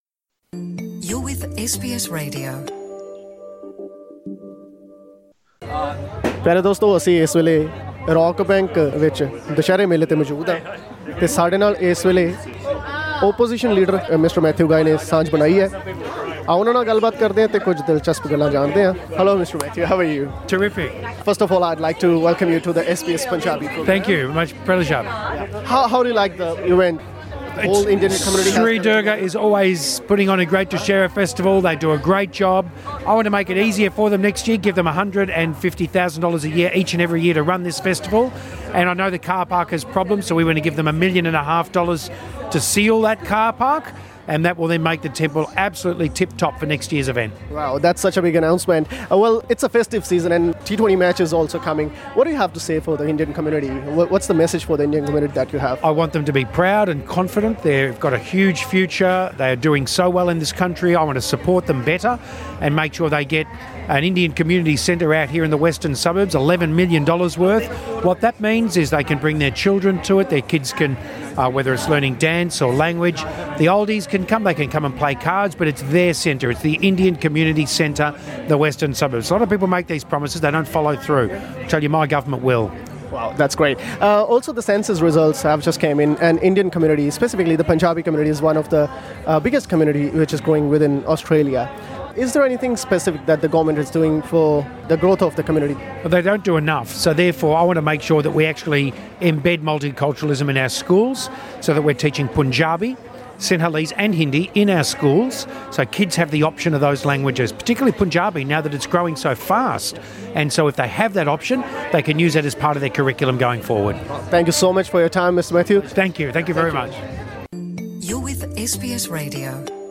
In an exclusive interview with SBS Punjabi, Victorian Liberal party leader Matthew Guy took an opportunity to reflect on his party’s commitment to supporting multicultural communities.